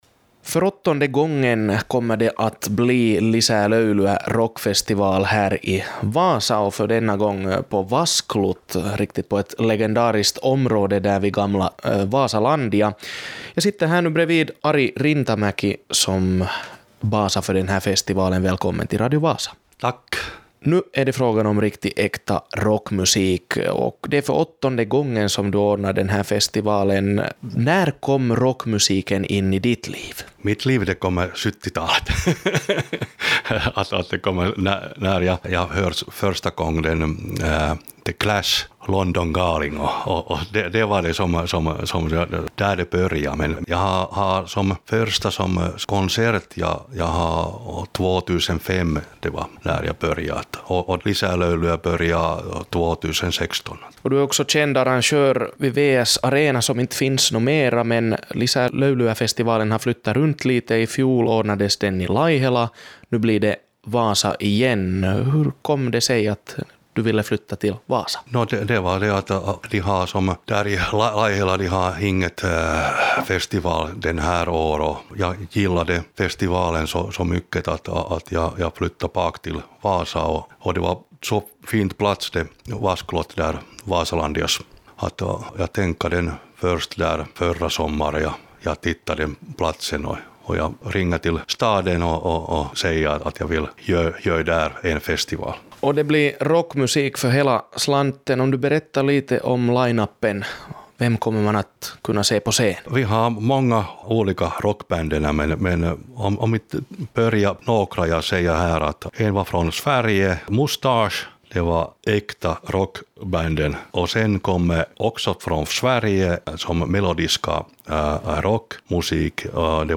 Haastattelussa